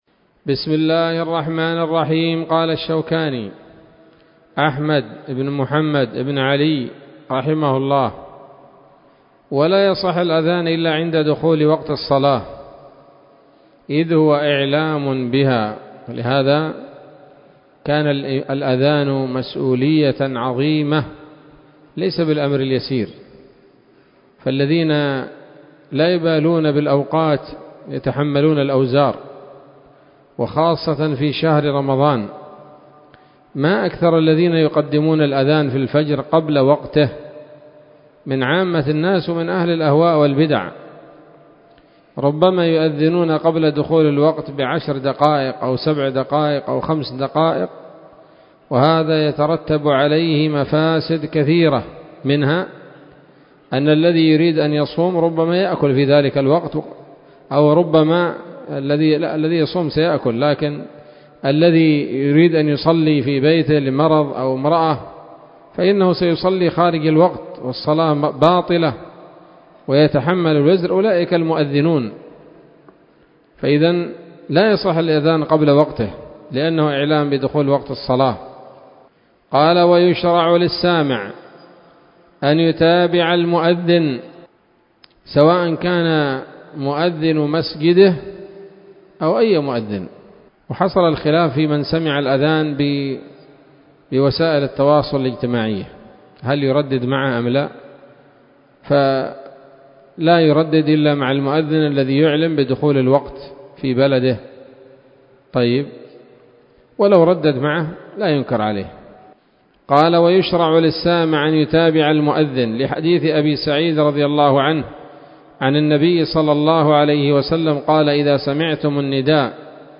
الدرس السابع من كتاب الصلاة من السموط الذهبية الحاوية للدرر البهية